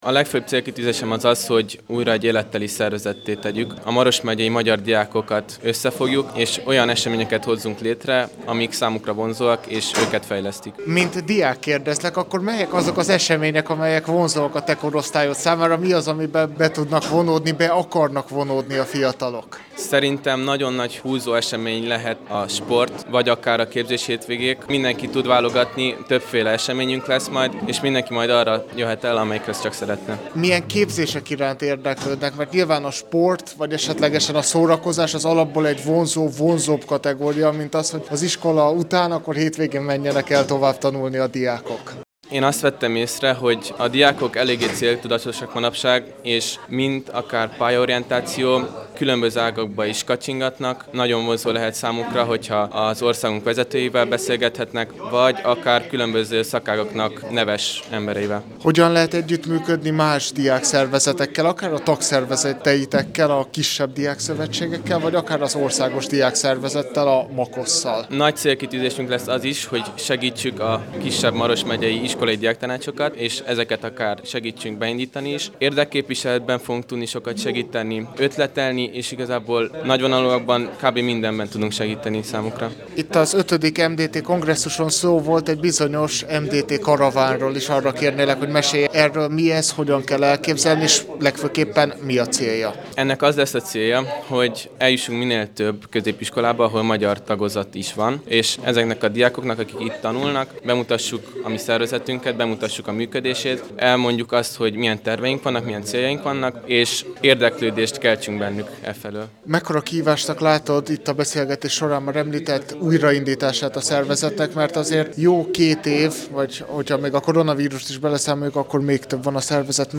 akivel